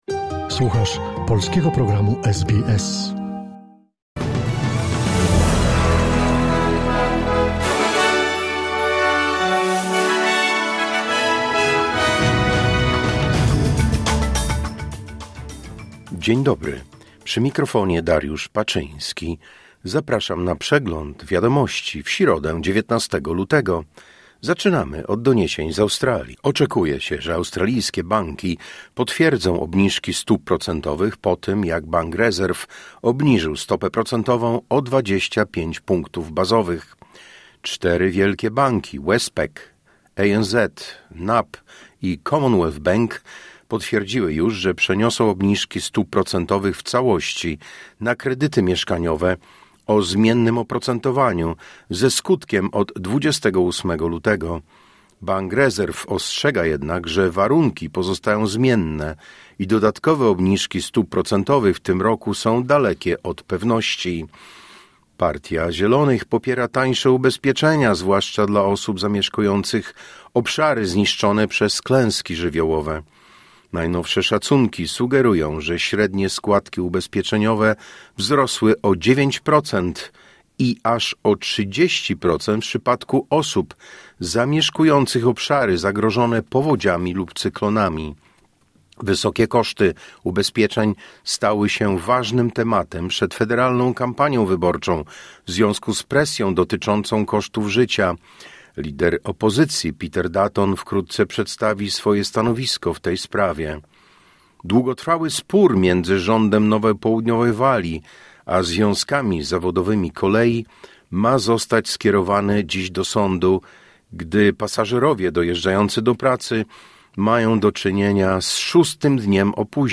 Wiadomości 19 lutego SBS News Flash